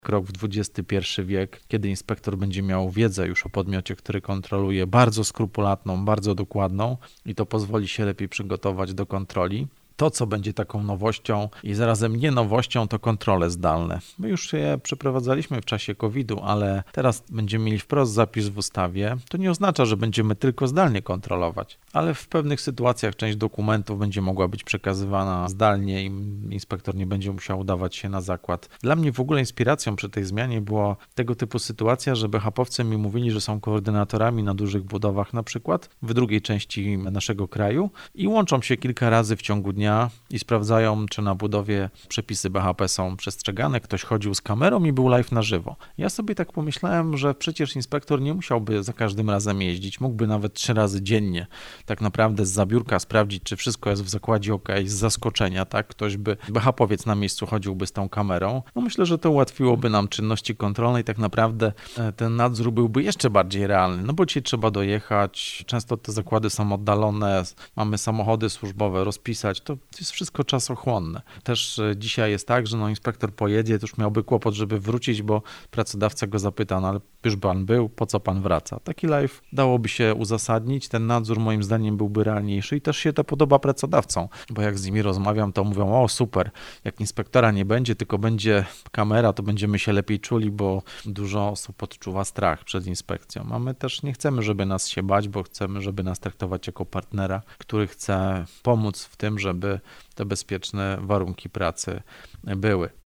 -To krok w XXI wiek – mówi minister Marcin Stanecki – Główny Inspektor Pracy.